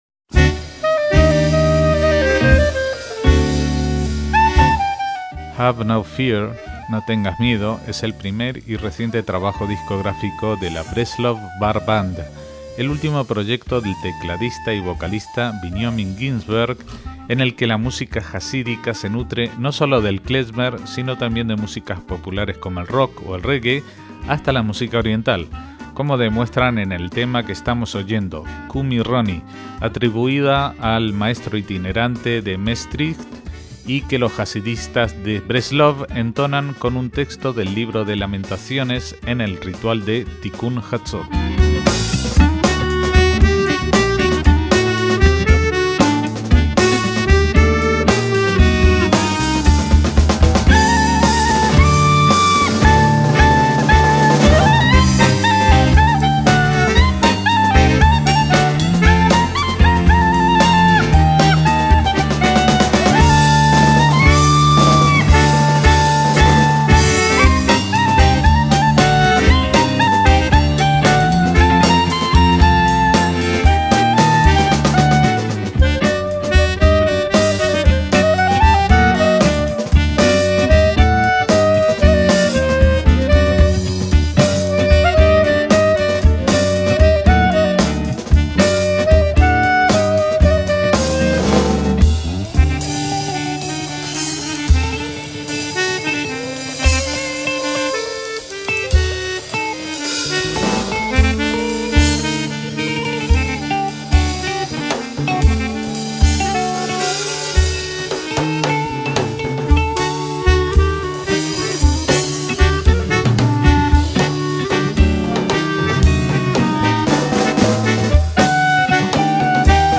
clarinete
saxos
guitarra eléctrica
percusión